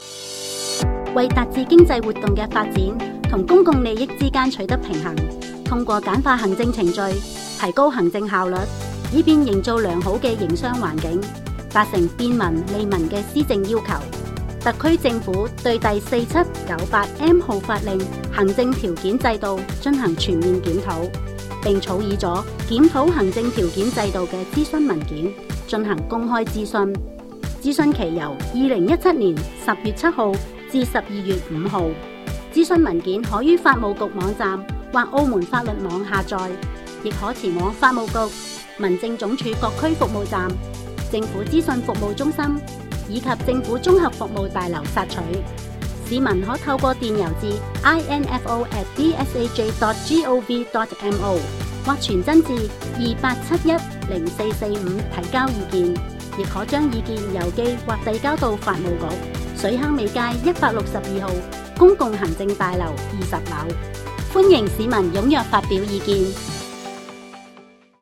附件其他資訊_行政准照_電台宣傳聲帶_cn_68s.mp3